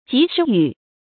及時雨 注音： ㄐㄧˊ ㄕㄧˊ ㄧㄩˇ 讀音讀法： 意思解釋： 指莊稼正需要雨水時下的雨。